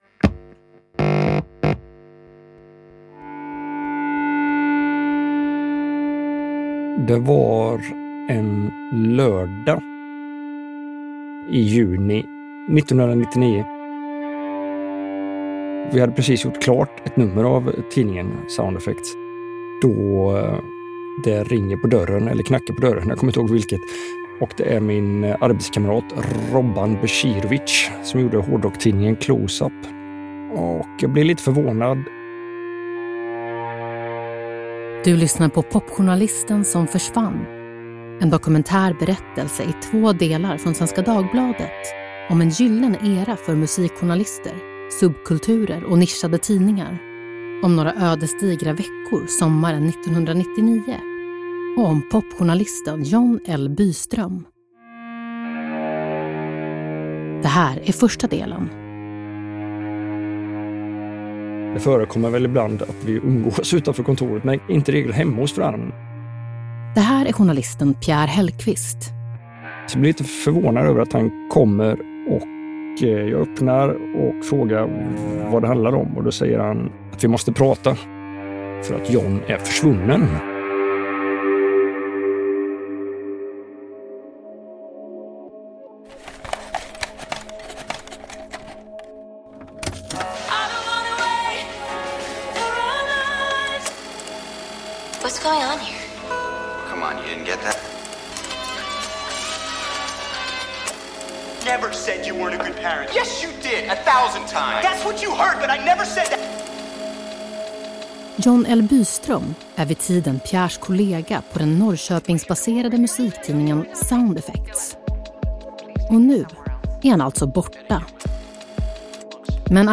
Genom vittnesmål från vänner och tidigare medarbetare växer bilden fram av ett liv i kulturtidningarnas kulisser, och frågor om ett mystiskt försvinnande 1999 som fortfarande inte fått svar.